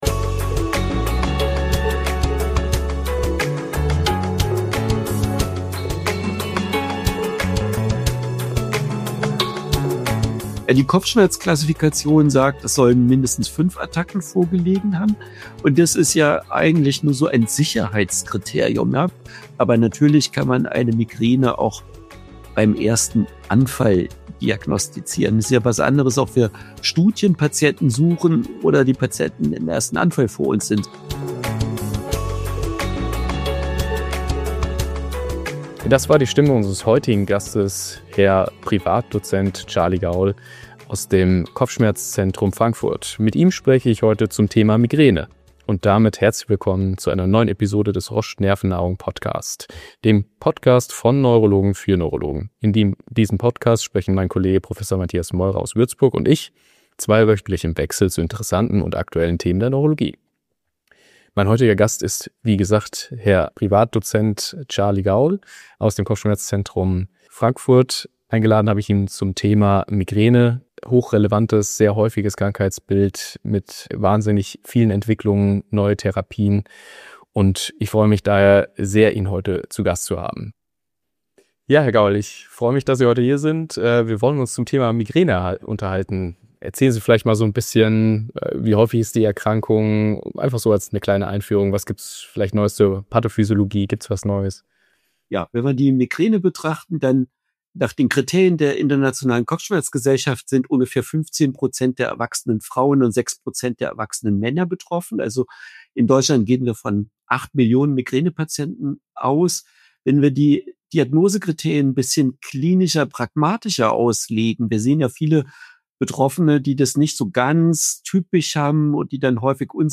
Gesprächs